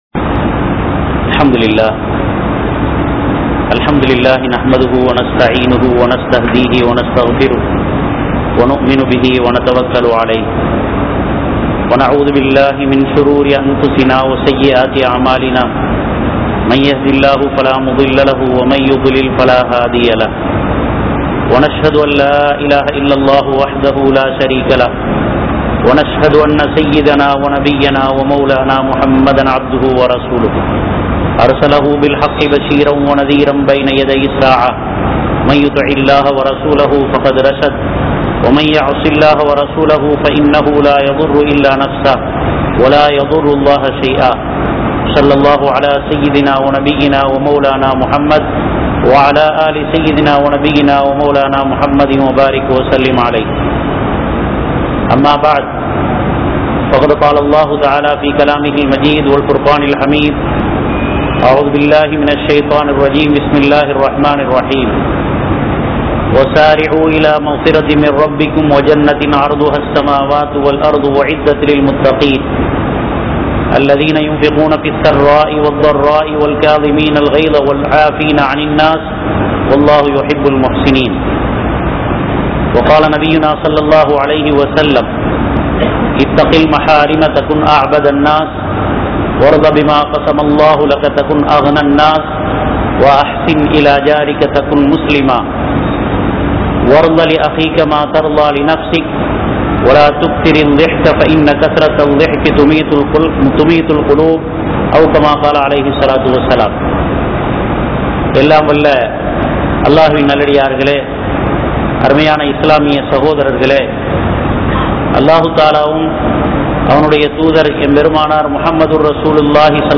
Ullaththai Alikkum Paavangal (உள்ளத்தை அழிக்கும் பாவங்கள்) | Audio Bayans | All Ceylon Muslim Youth Community | Addalaichenai
Kollupitty Jumua Masjith